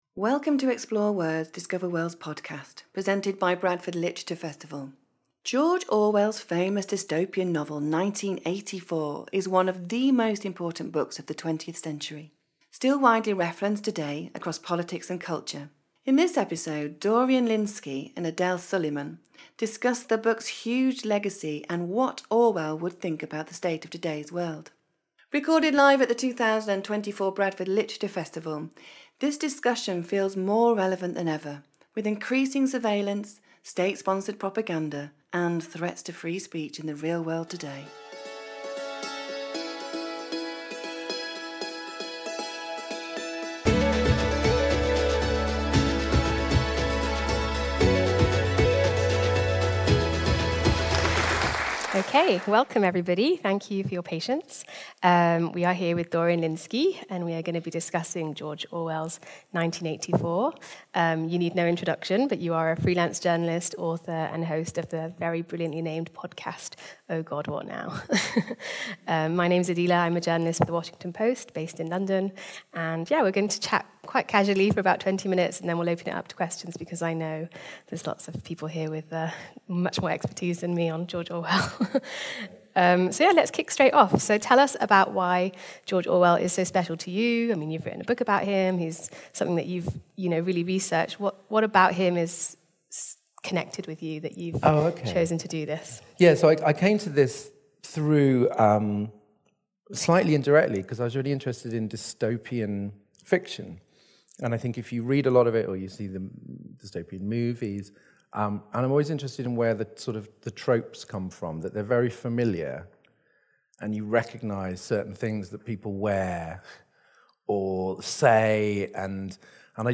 Join us for a discussion about this book’s huge legacy and what George Orwell would think about the state of today’s world.